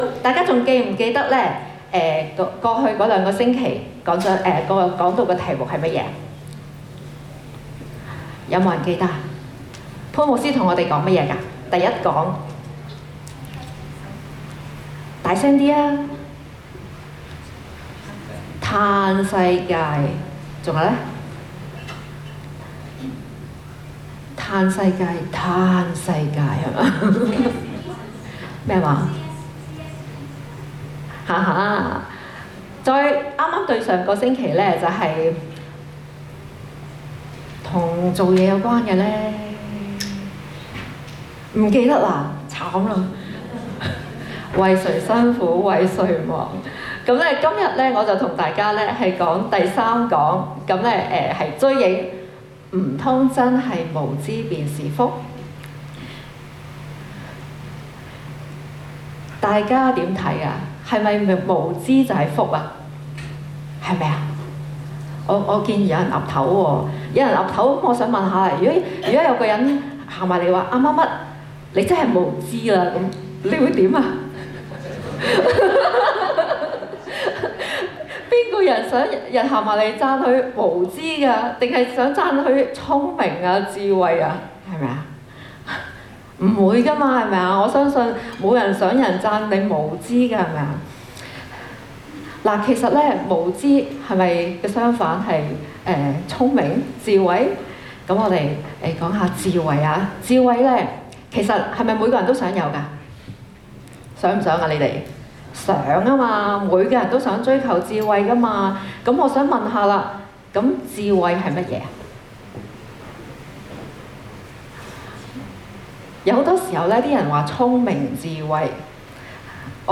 Chasing Shadows Guest Speaker April 7, 2024 Current Sermon 唔通真的“無知便是福”？